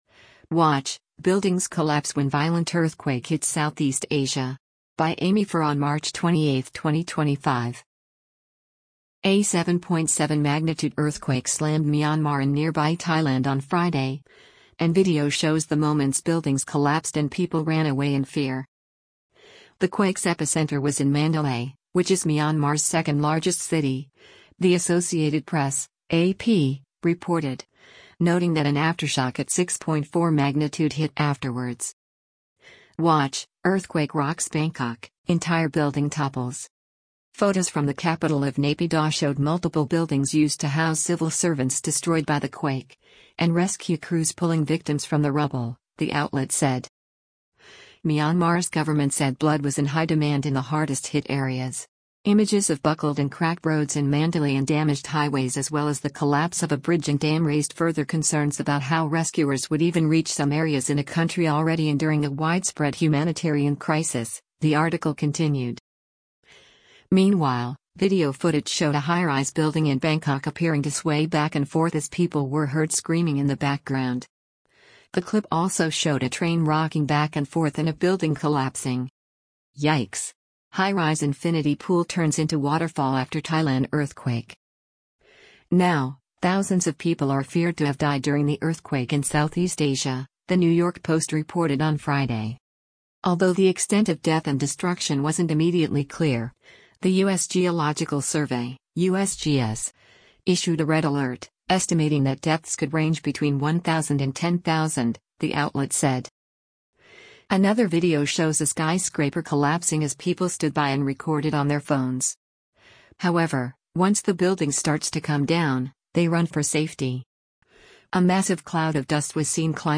Meanwhile, video footage showed a high-rise building in Bangkok appearing to sway back and forth as people were heard screaming in the background.